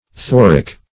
Search Result for " thoric" : The Collaborative International Dictionary of English v.0.48: Thoric \Thor"ic\, a. (Chem.)